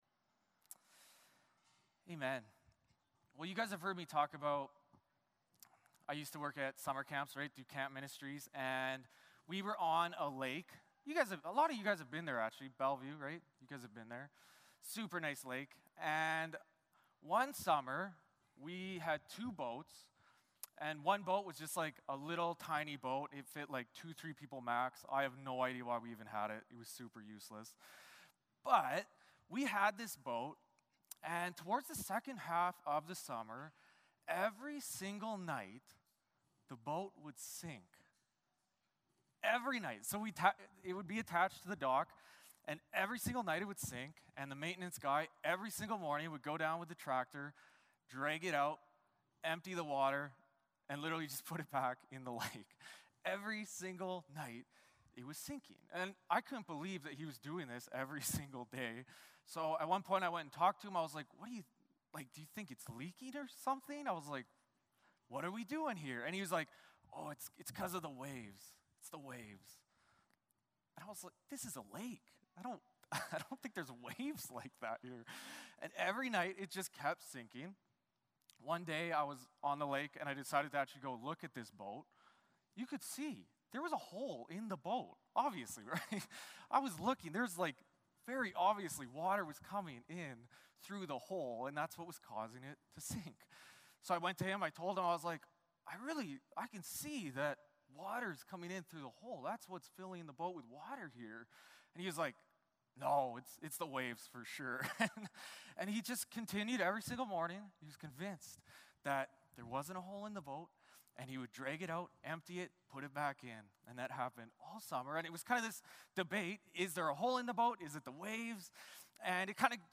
John 16:6-7 Service Type: Sunday Morning Service Passage